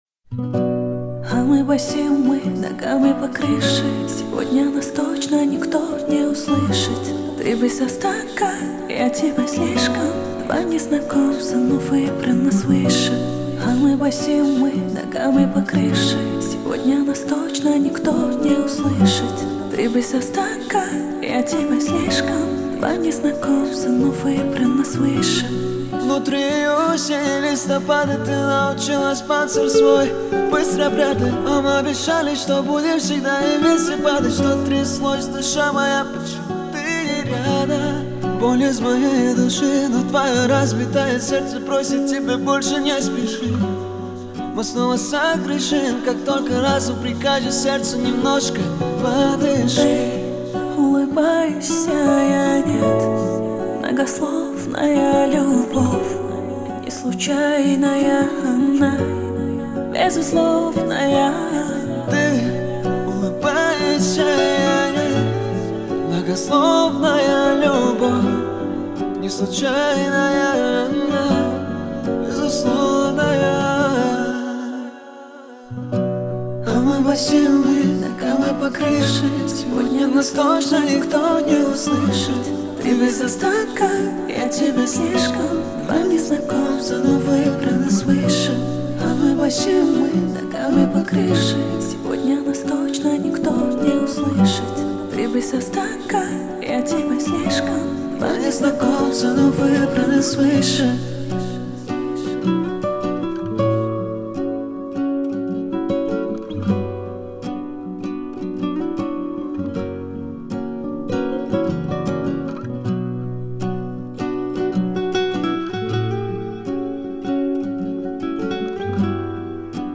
Рэп, Узбекская музыка